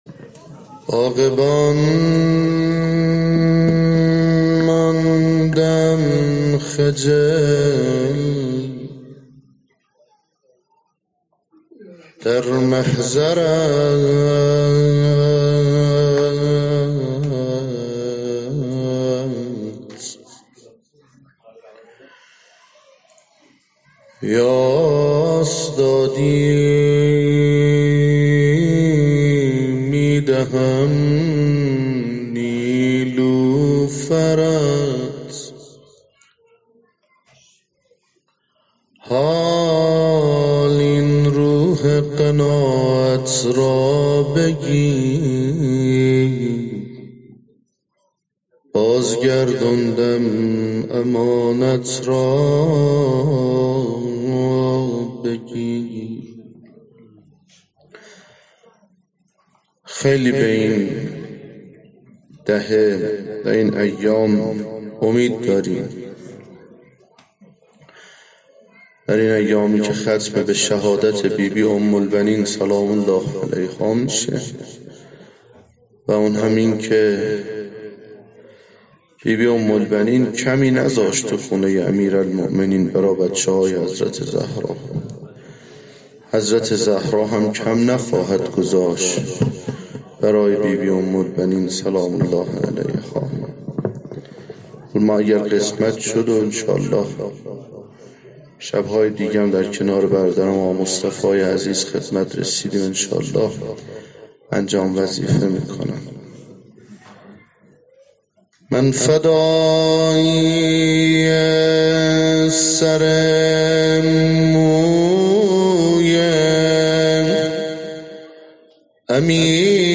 هیئت خدمتگزاران اهل بیت علیهم السلام
روضه‌ی حضرت ام‌البنین سلام الله علیها